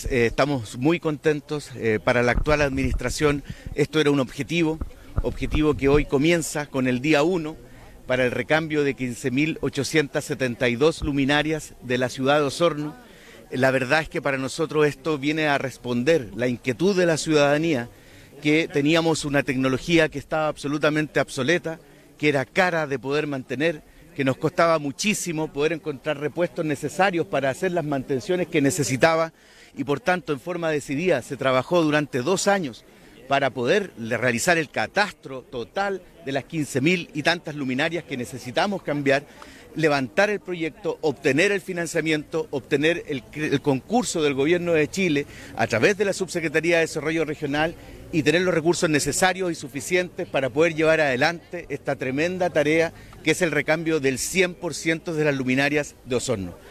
Este martes, se dio inicio al recambio de 15.872 luminarias en el radio urbano de Osorno, en un acto celebrado en la Plaza España de Rahue Alto.
El Alcalde (S) de Osorno, Claudio Villanueva, resaltó que este objetivo es el resultado de dos años de trabajo colaborativo, que viene a responder a las solicitudes de los vecinos, pues las anteriores luminarias contaban con tecnología que se encontraba obsoleta.